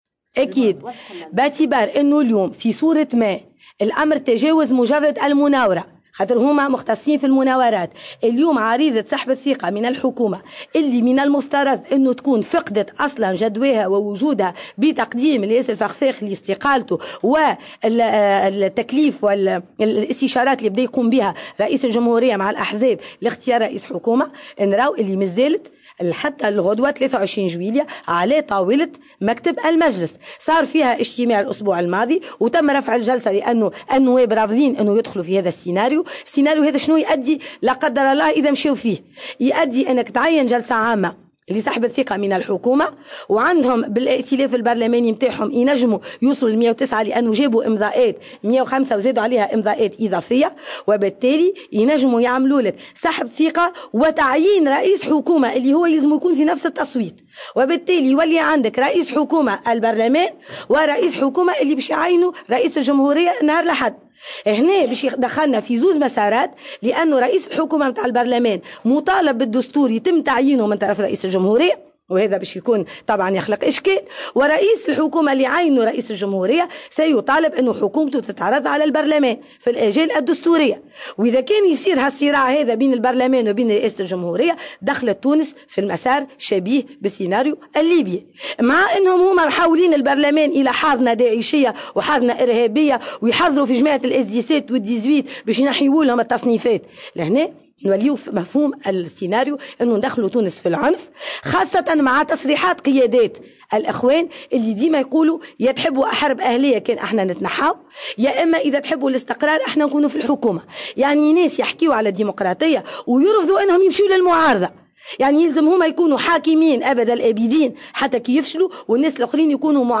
وقالت موسي، خلال ندوة صحفية، اليوم الأربعاء في مقر مجلس نواب الشعب، إن عريضة سحب الثقة، والتي تعتبر لاغية قانونا بعد استقالة حكومة إلياس الفخفاخ، لا تزال معروضة أمام مكتب المجلس، منبهة إلى أن صراعا من مثل هذا النوع بين الربلمان ورئاسة الجمهورية قد يؤدي إلى سيناريو شبيه بالسيناريو الليبي، وفق تعبيرها.